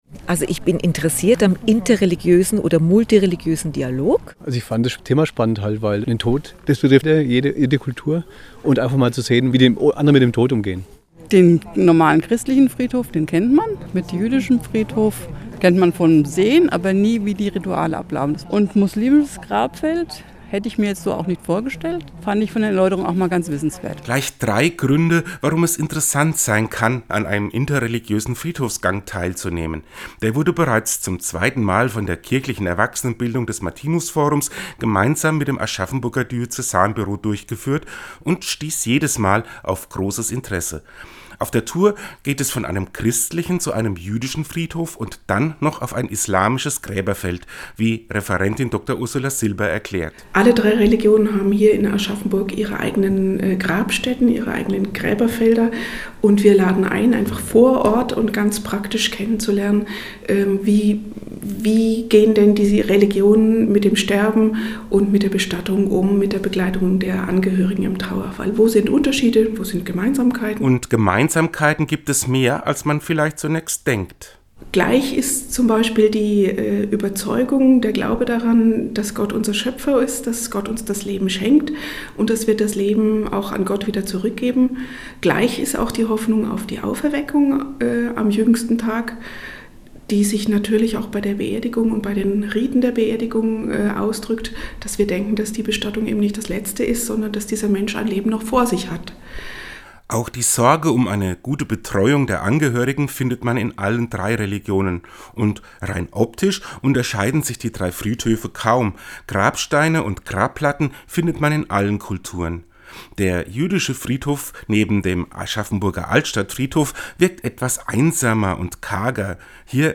Den Radiobericht finden Sie unten als Download!